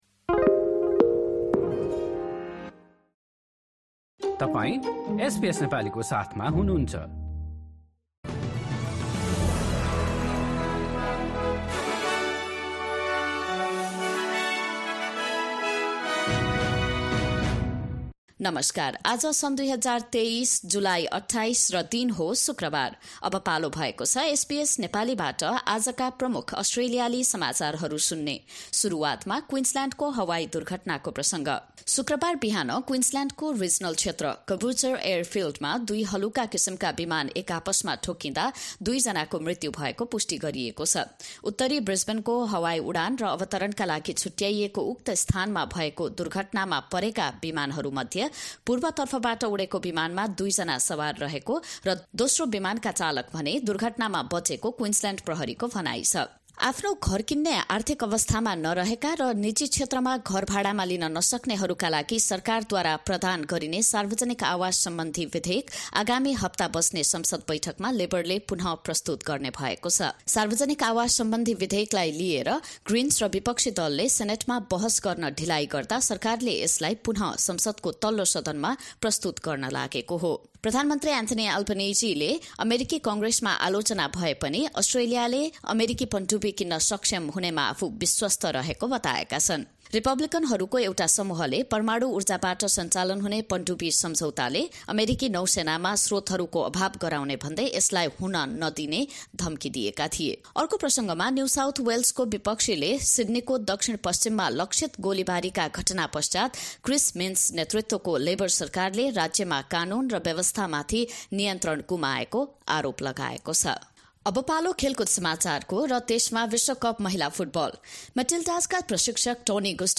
एसबीएस नेपाली प्रमुख अस्ट्रेलियाली समाचार: शुक्रवार, २८ जुलाई २०२३